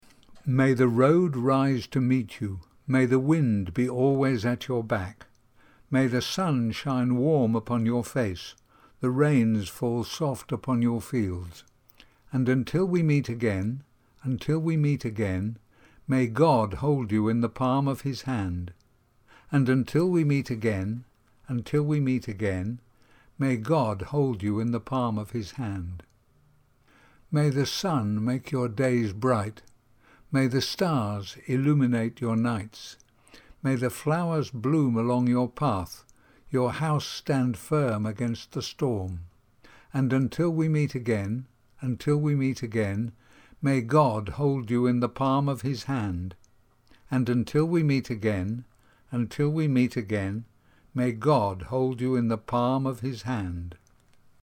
Fichiers de prononciation
An Irish Blessing Pronunciation.mp3